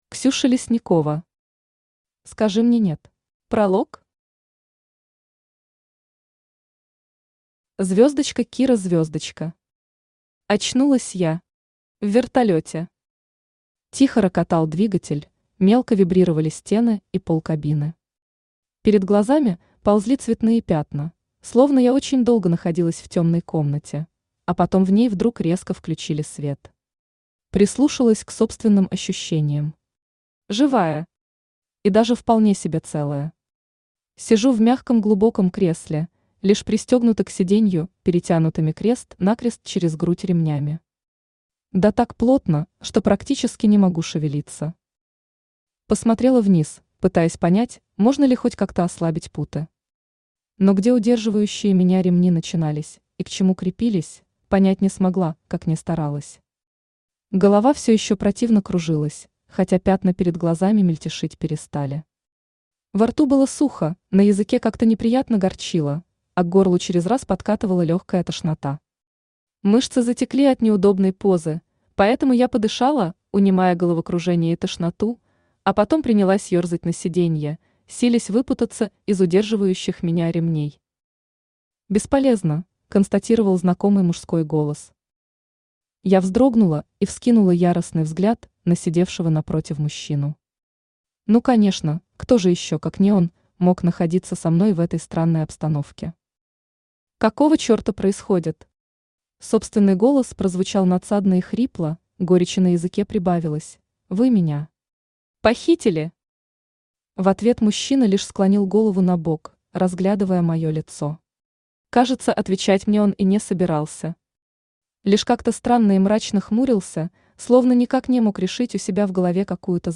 Аудиокнига Скажи мне «нет» | Библиотека аудиокниг
Aудиокнига Скажи мне «нет» Автор Ксюша Лесникова Читает аудиокнигу Авточтец ЛитРес.